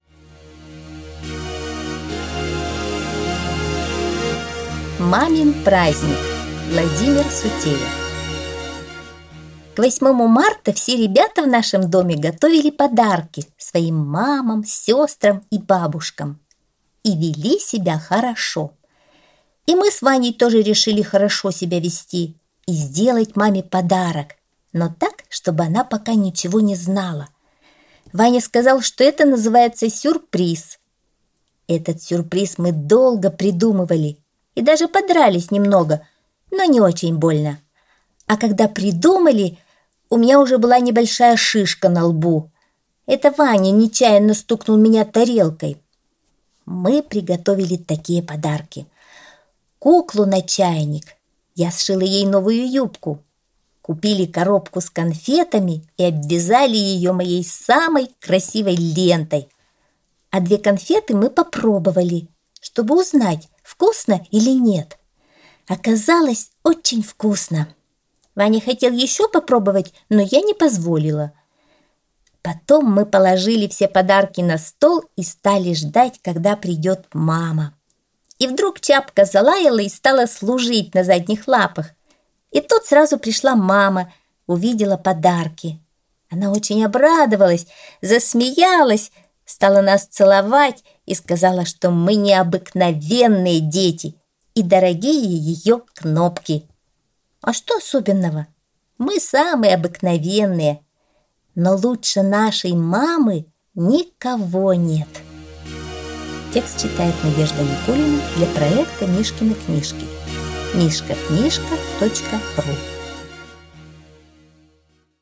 Аудиосказка «Мамин праздник»